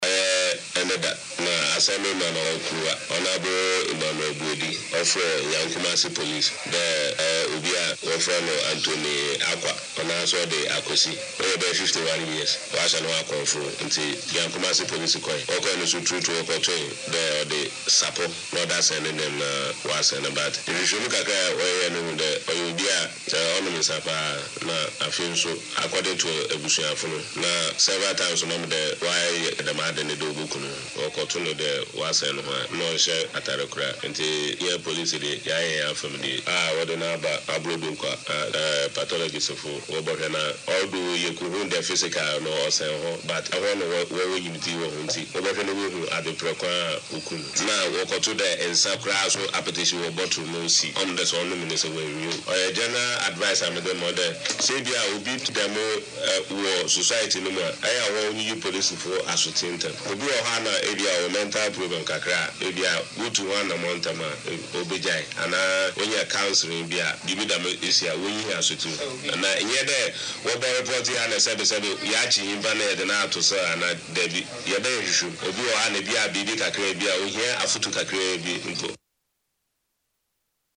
Listen to the police